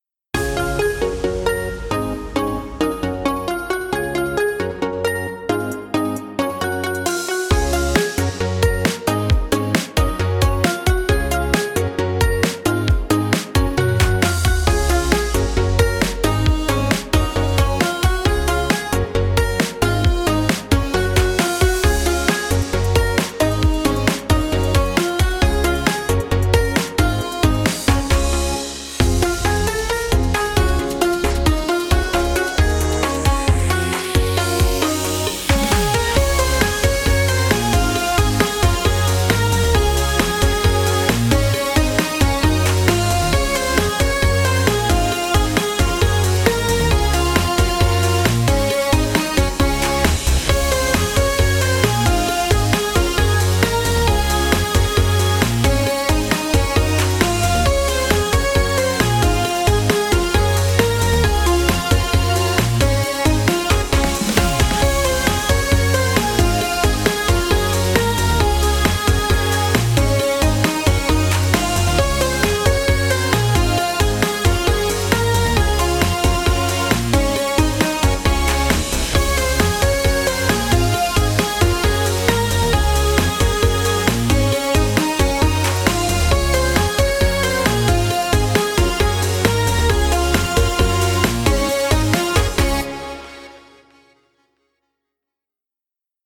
ポップで明るい感じのかわいいEDM風BGMです。